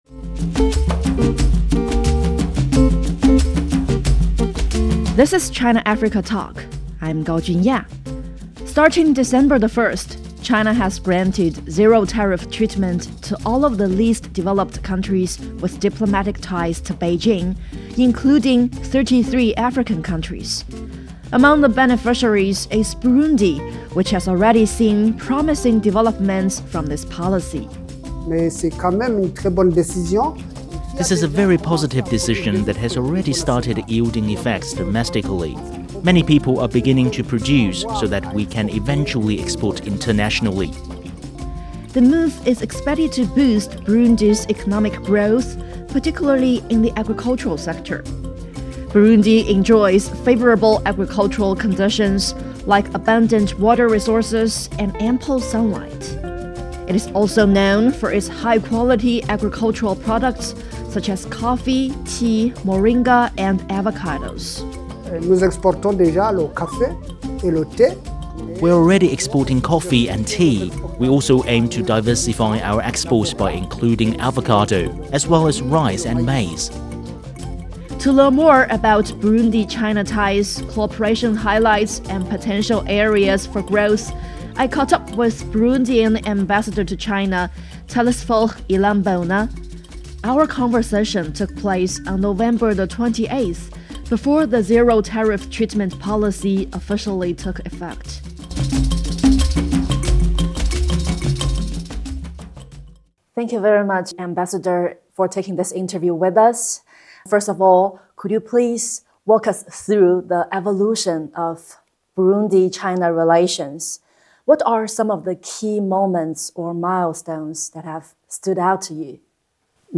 In a recent interview, Burundian Ambassador to China, Telesphore Irambona, highlighted the growing friendship between the two nations and outlined ambitious plans for collaboration in agriculture, infrastructure, and energy.